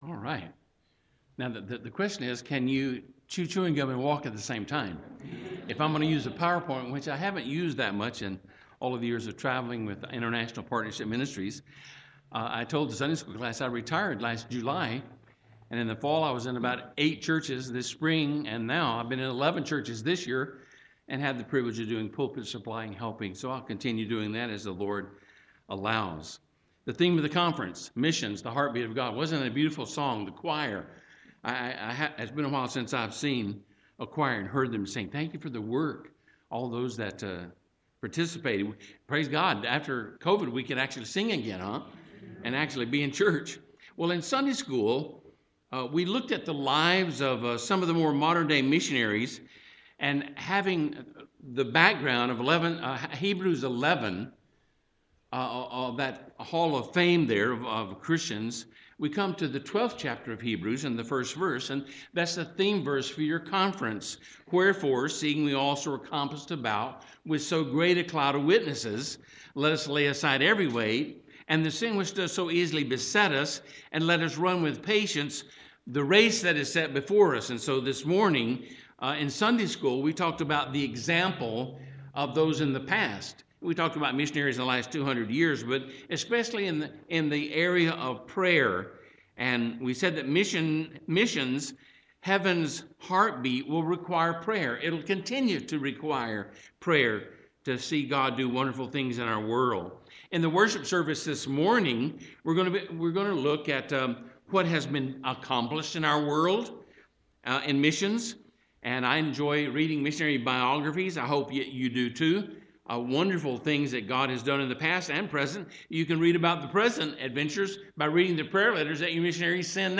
Missions Conference AM
Sermon